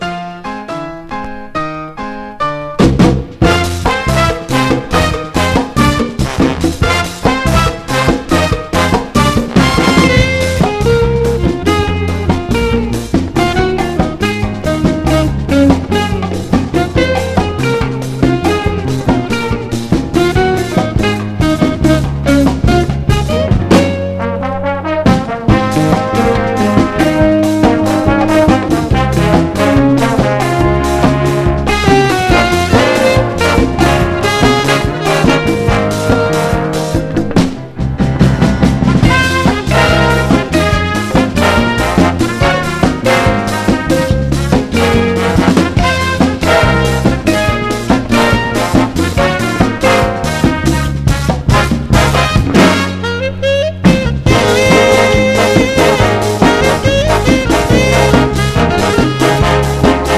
JAZZ / DANCEFLOOR / JAZZ FUNK / BIG BAND
ライブラリー好きにも推薦のユーロ・ビッグ・バンド・ジャズ・ファンク・グルーヴ！
ビッグ・バンド編成で繰り広げられるジャズ・ファンク的アレンジが魅力です。